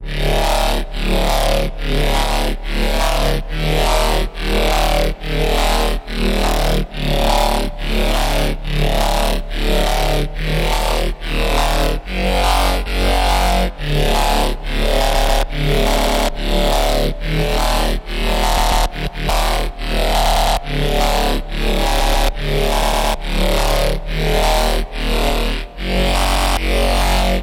140 Bpm的硬镲片槽
描述：使用Ableton Live 8创建的Dubstep鼓循环。